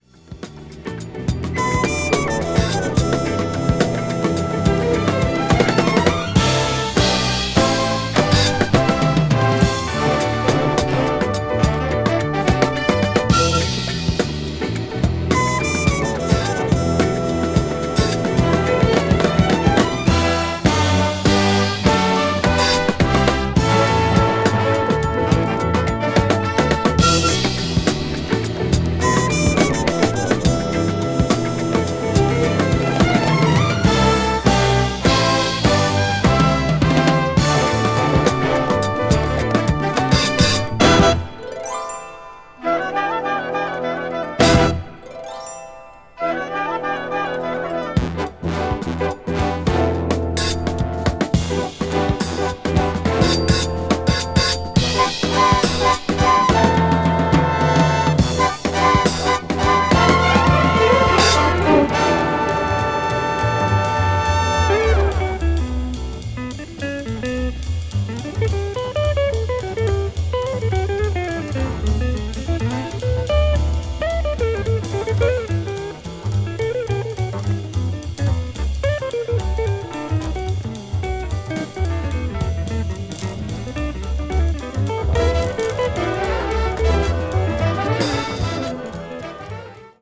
media : EX/EX(some slightly noises.)